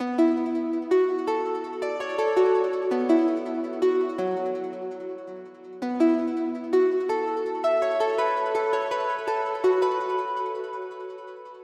Tag: 165 bpm Ambient Loops Synth Loops 1.96 MB wav Key : B